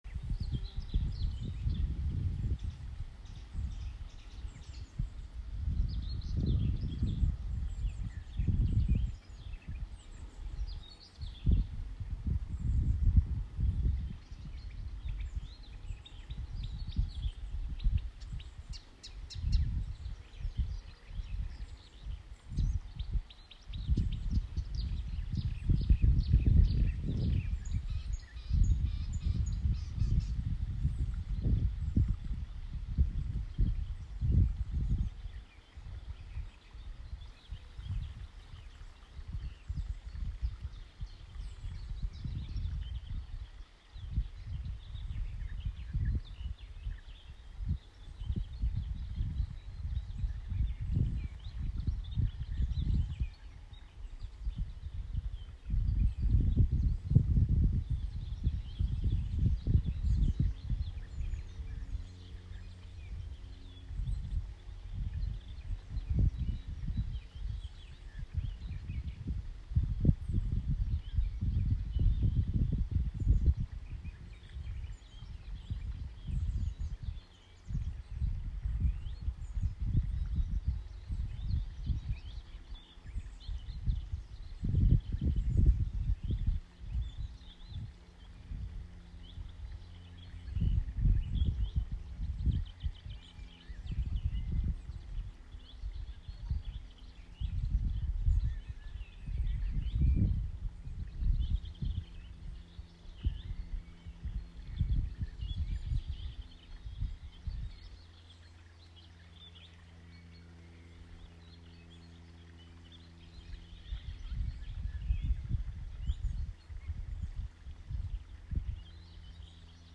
skov.mp3